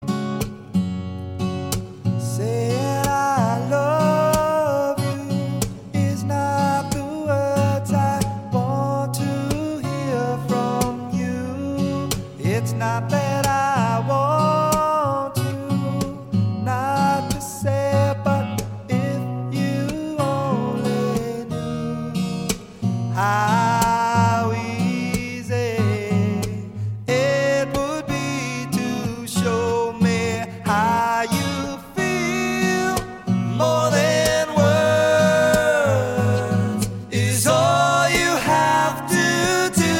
классический рок , блюз-рок , рок , поп